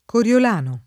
Coriolano [ kor L ol # no ]